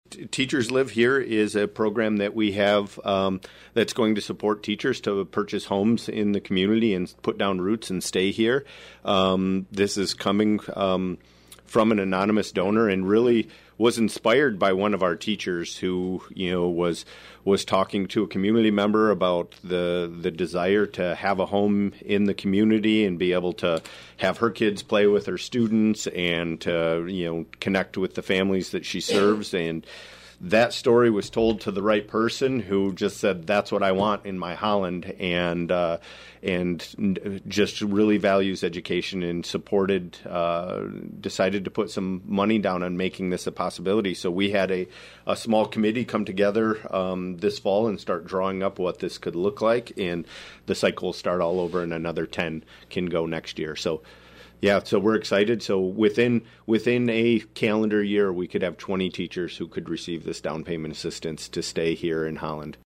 “WHTC Talk of the Town” appearance.